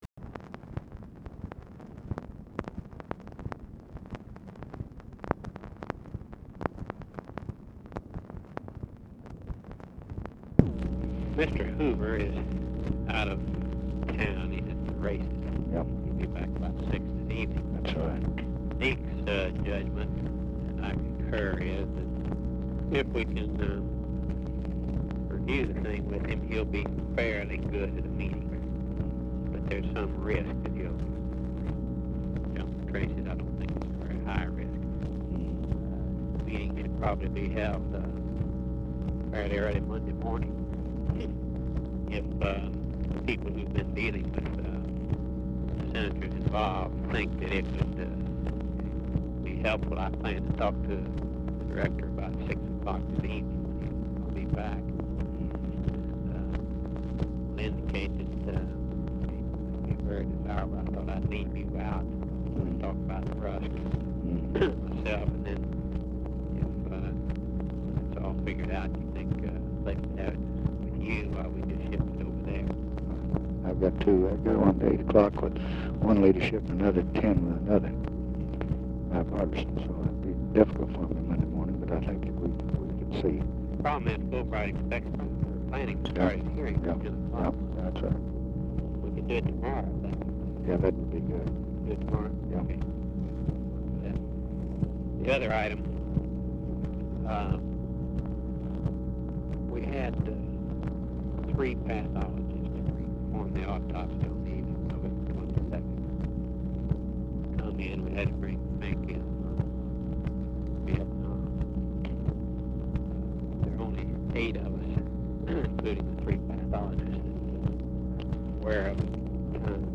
Conversation with RAMSEY CLARK, January 21, 1967
Secret White House Tapes